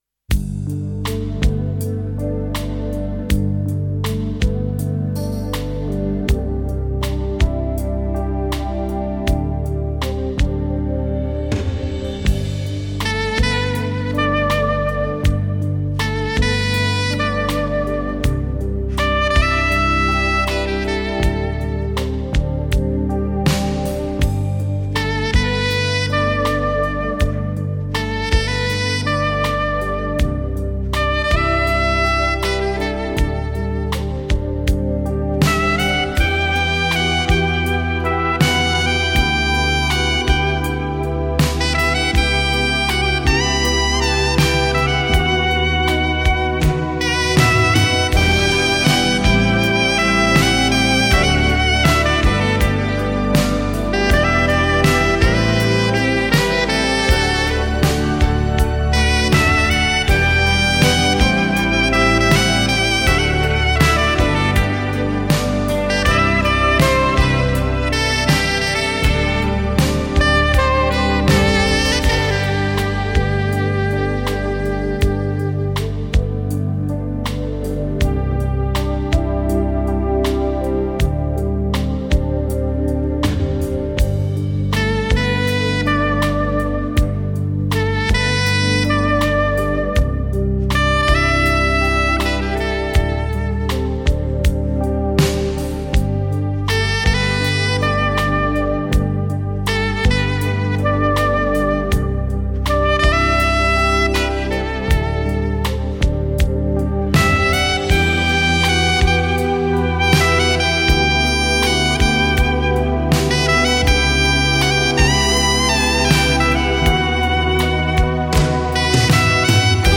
超低音、多层次、高定位的试音典范之作，试音终极者！